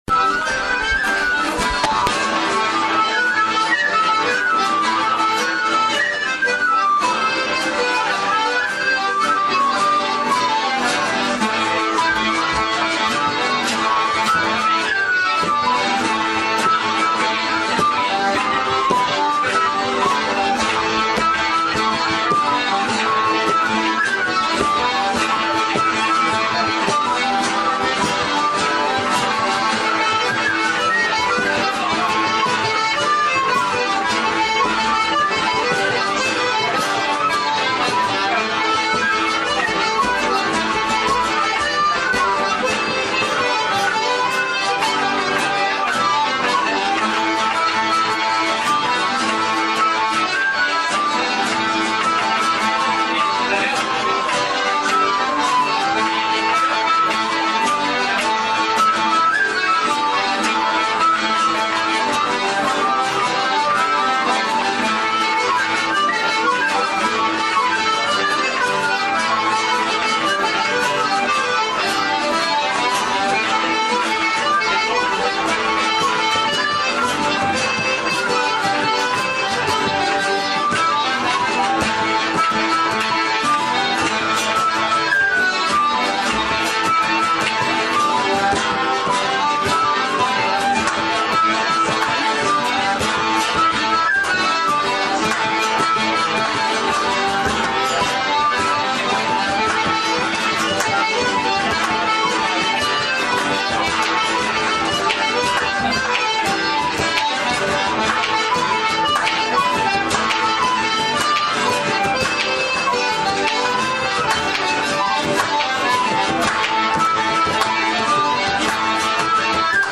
21 Juin 2007: Fête de la Musique, Photos >> et Audio